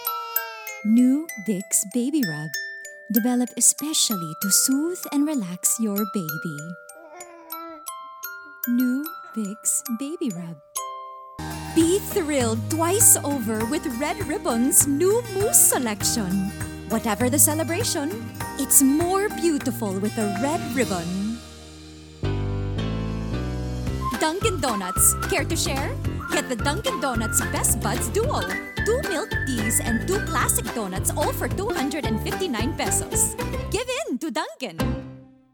Browse all of our Tagalog voice over artists, listen to their show reels, read their voice notes and view their photos.
Tagalog, Female, Home Studio, 20s-30s